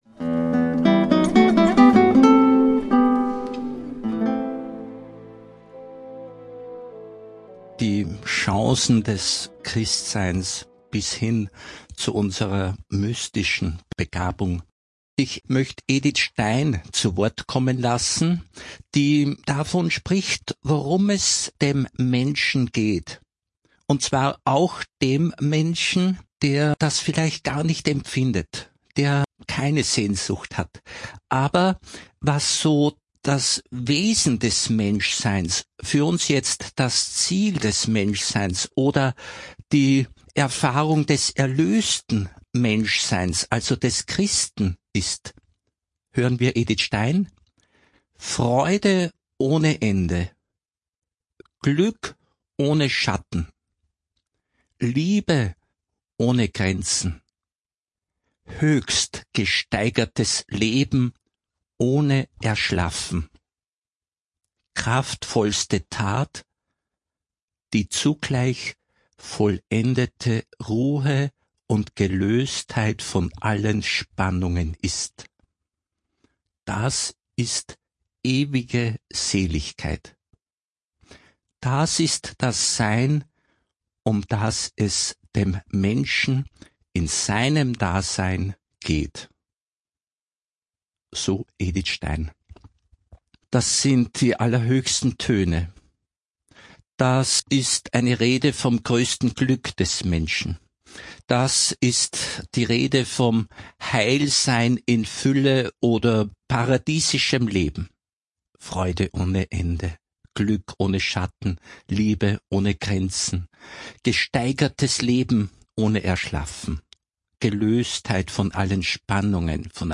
(Radio Maria Aufzeichnung vom 26.2.2025)